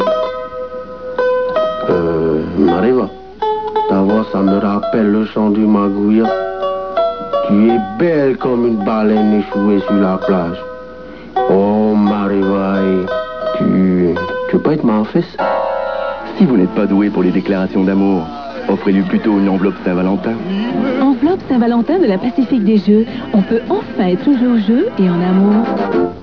Publicités radio
Ici, notre poete en savates tire son inspiration du cri du margouillat, ou d'une baleine échouée sur la plage.